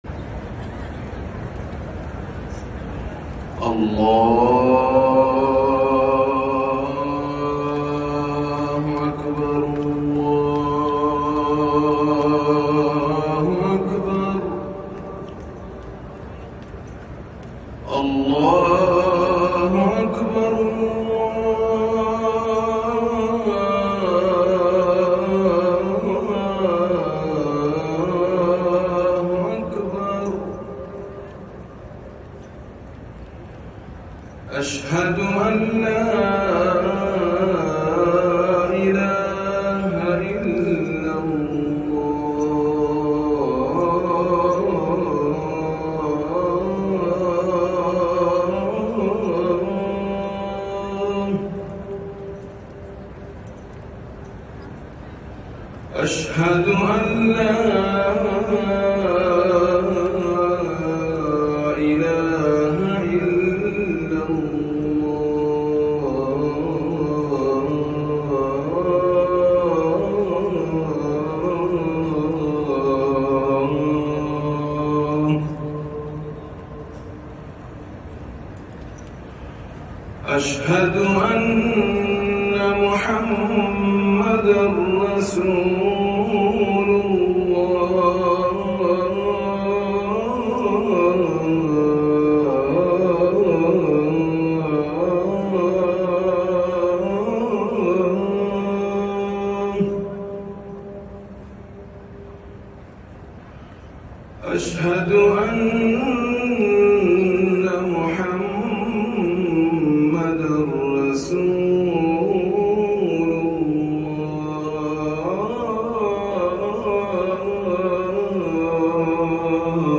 أذان الفجر الأول
الأذان الأول لصلاة الفجر